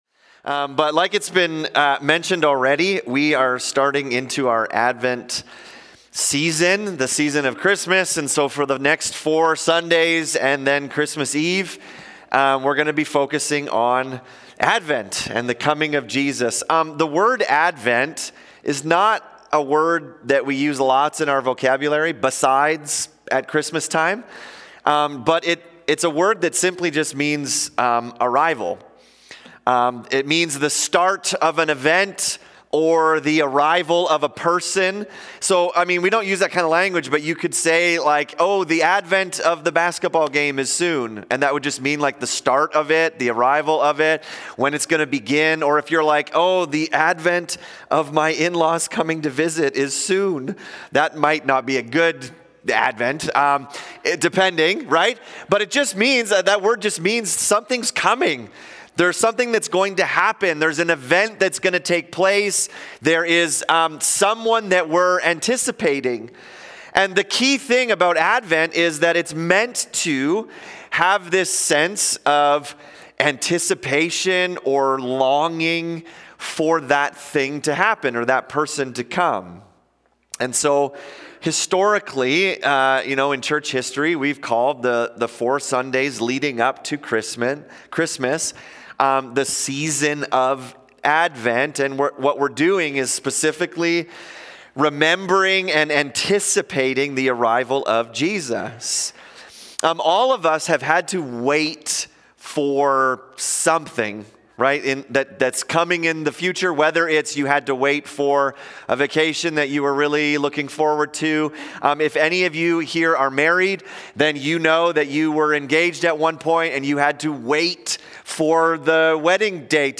Sermons | North Peace MB Church